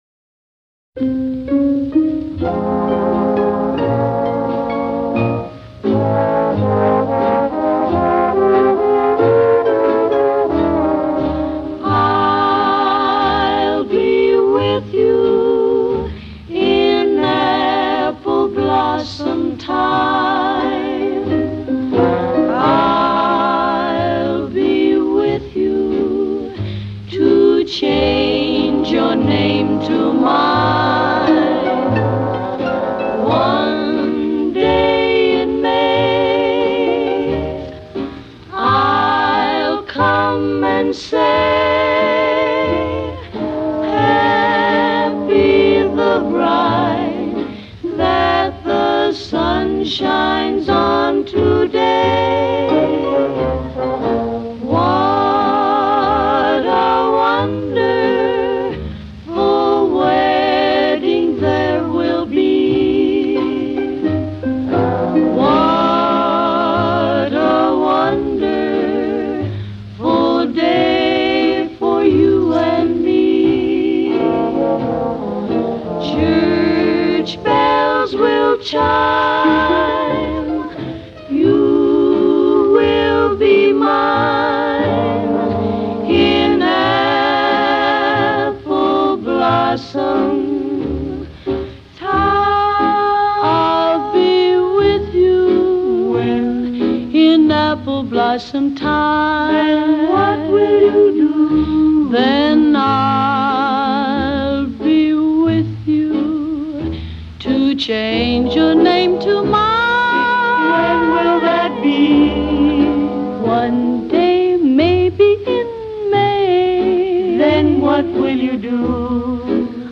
Genre: Traditional Pop, Vocal Jazz, Easy Listening